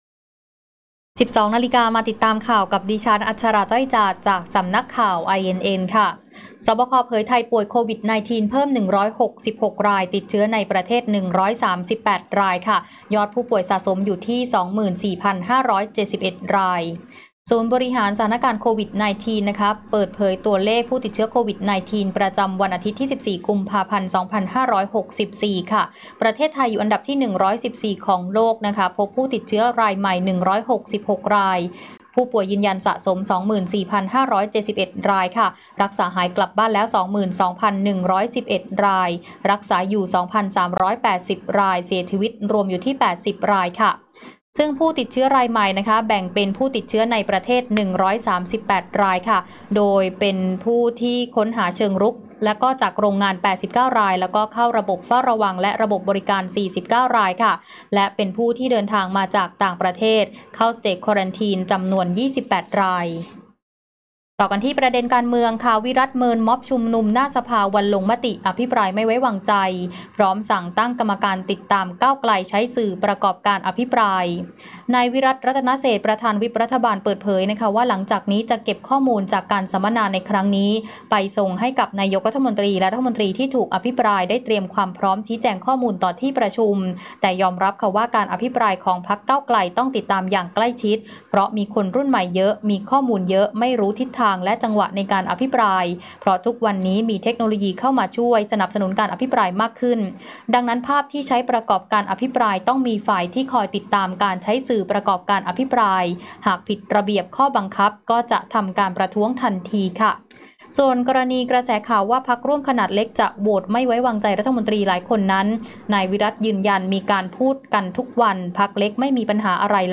ข่าวต้นชั่วโมง 12.00 น.